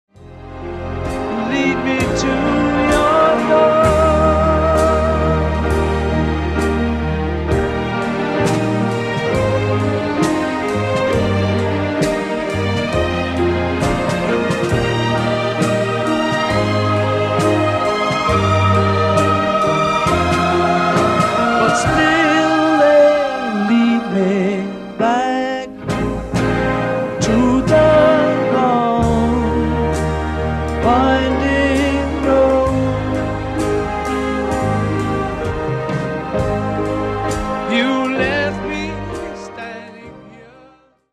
orchestrali